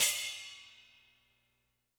R_B Splash A 01 - Close.wav